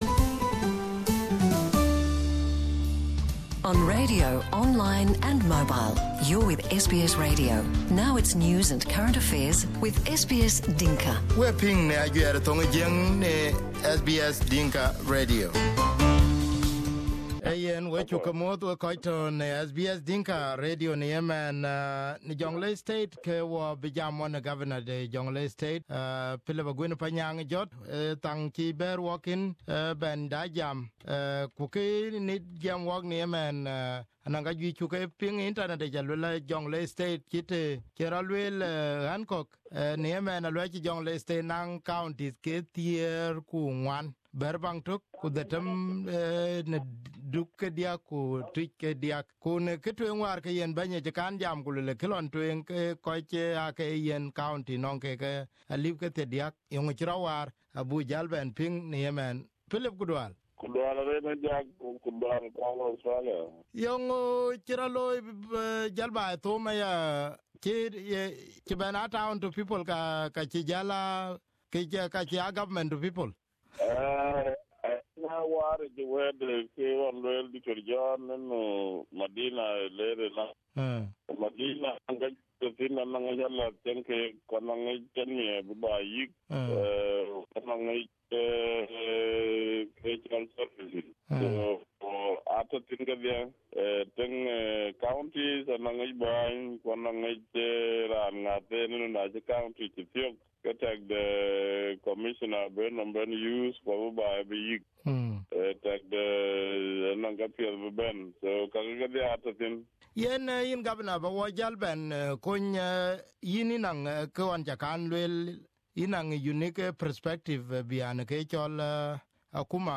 Here is the interview with Philip Aguer